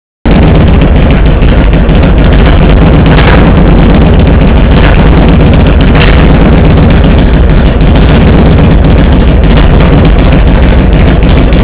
なかなかの爆音です(^_^;)　でも、走るとパンチが効いた音質とバタバタ感がイイ！！